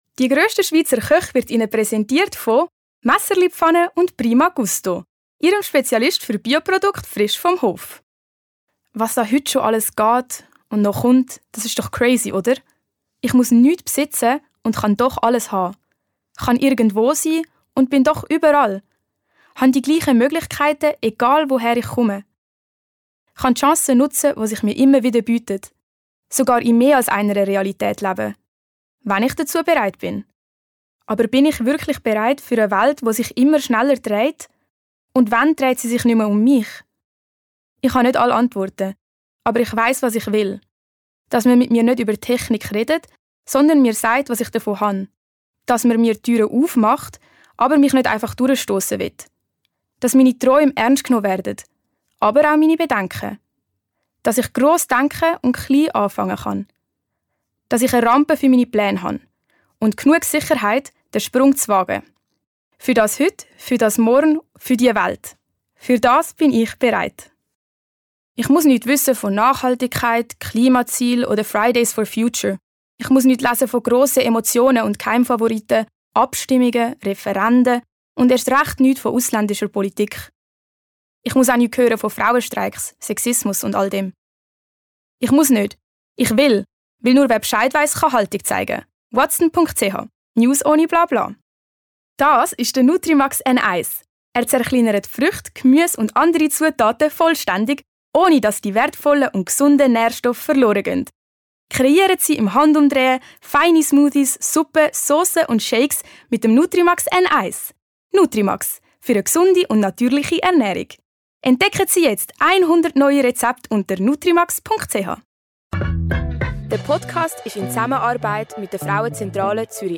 Werbung Schweizerdeutsch (ZH)